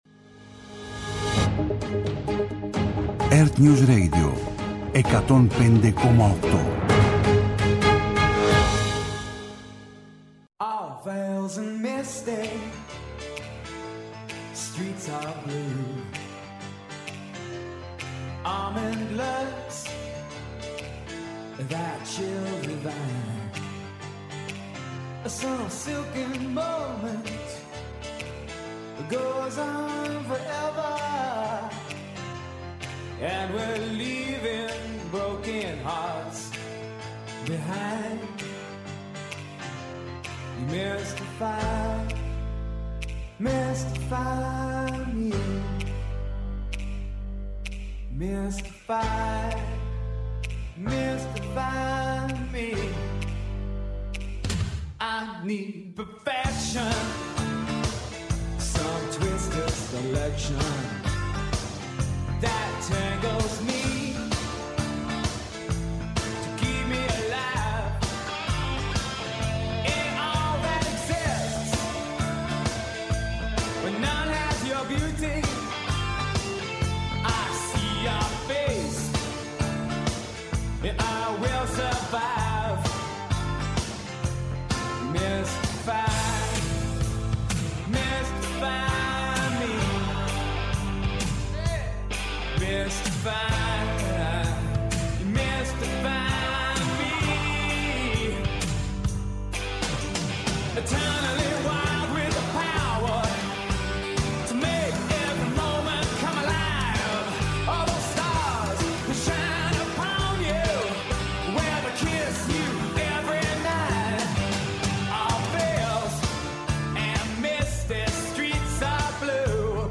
-Συνδέσεις με ΕΡΤnews